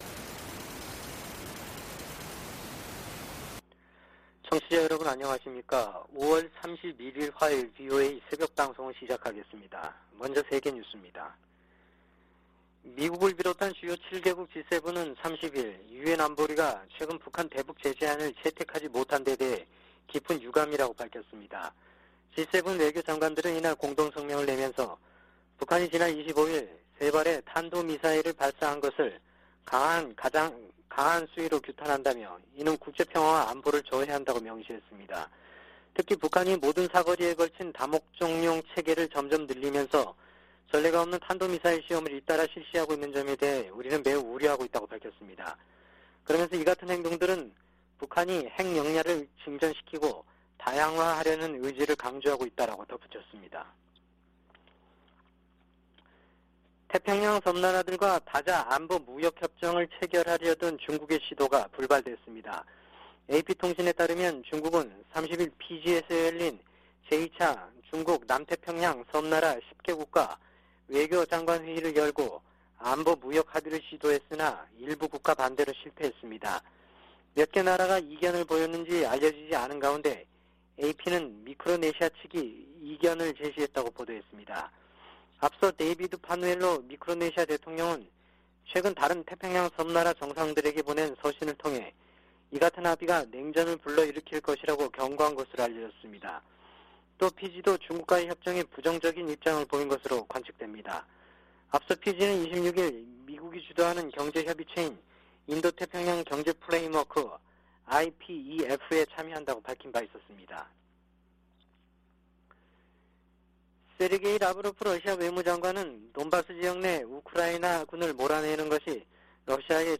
VOA 한국어 '출발 뉴스 쇼', 2022년 5월 31일 방송입니다. 미 재무부가 북한의 최근 탄도미사일 발사에 대응해 북한 국적자와 러시아 기관들을 추가 제재했습니다. 미한일 외교장관들은 유엔 안보리가 새 대북 결의안 채택에 실패한 데 유감을 나타내고 3국 협력은 물론 국제사회와의 조율을 강화하겠다고 밝혔습니다.